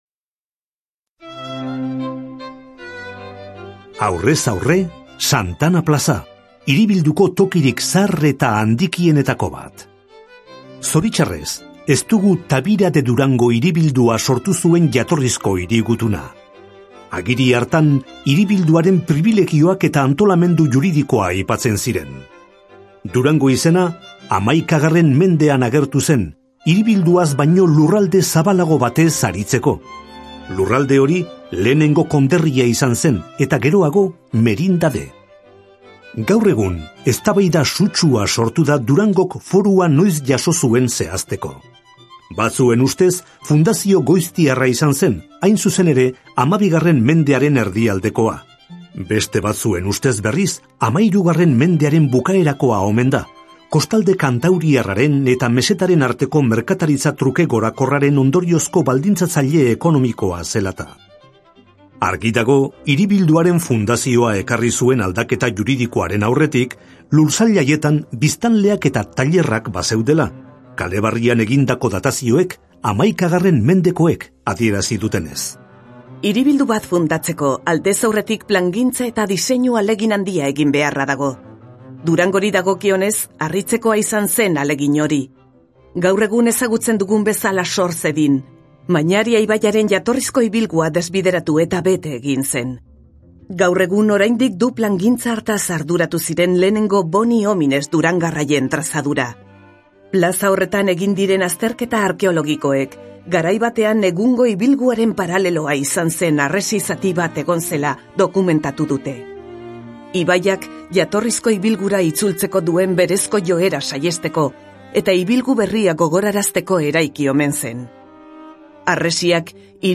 BISITA AUDIOGIDATUAK DURANGON - VISITAS AUDIOGUIADAS EN DURANGO